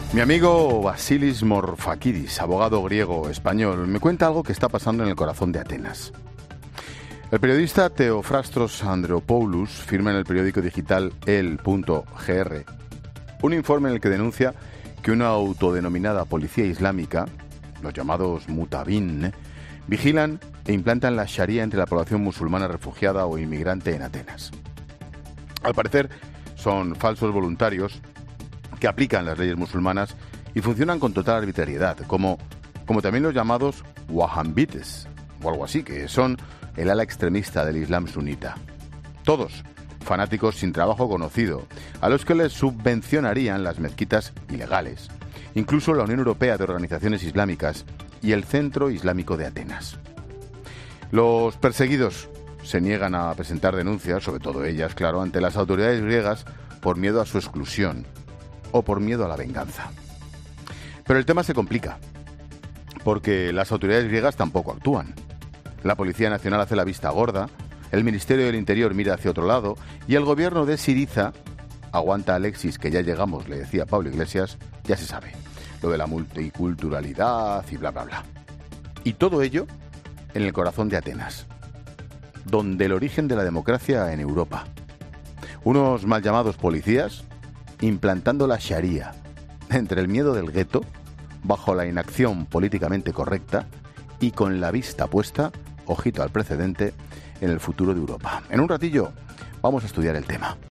Monólogo de Expósito
Monólogo de Ángel Expósito a las 17h. sobre la actuación de una autodenominada policía islámica que vigila e implanta la sharia entre la población musulmana refugiada en Atenas.